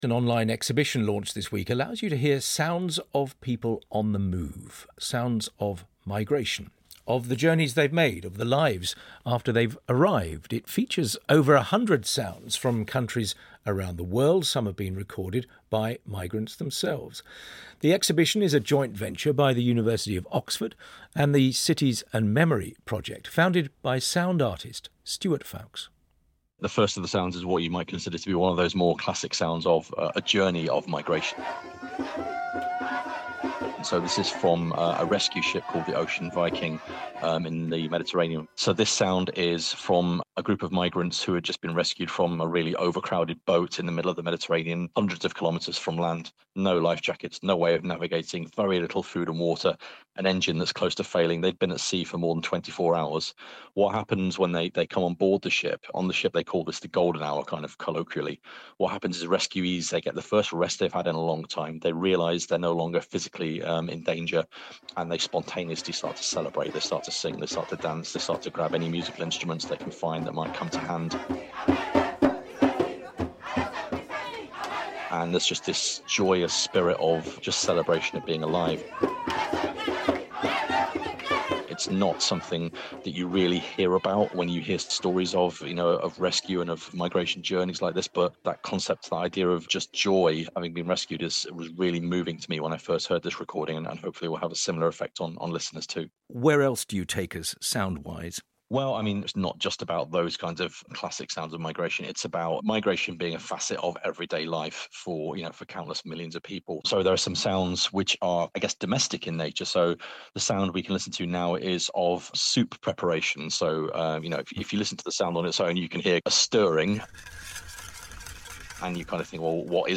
BBC World Service interview on Migration Sounds